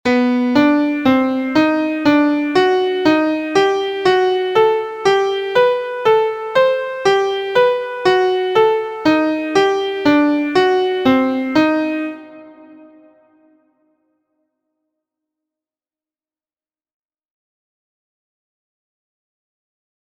• This example shows both permutations of a 2 note modal sequence in B Phrygian b4 scale using 3rds ascending and descending one octave.